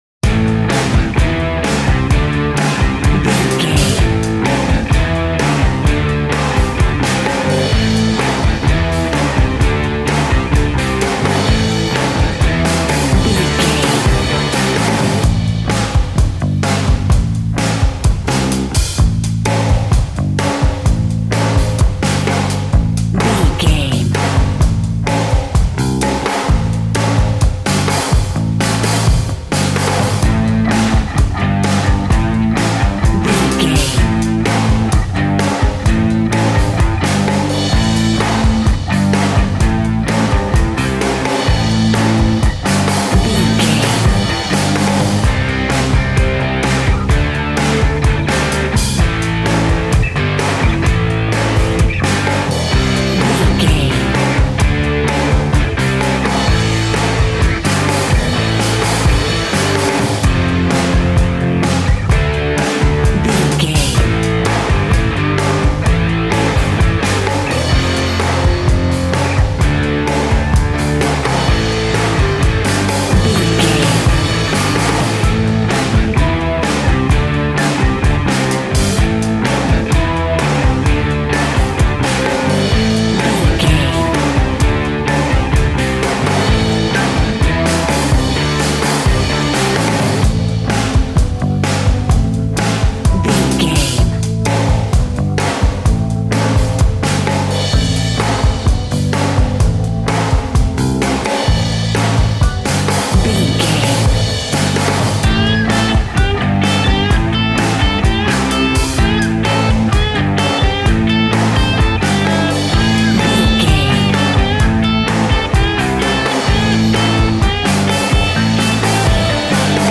Ionian/Major
A♭
hard rock
heavy metal
distortion
instrumentals